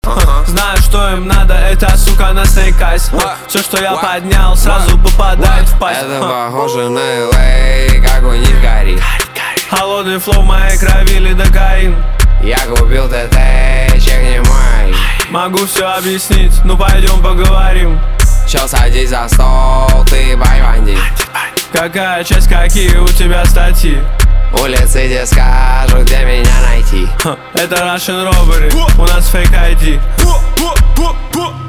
русский рэп
битовые , басы , пацанские , матерные , жесткие